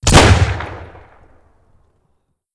Shotgun1_Shoot 02.wav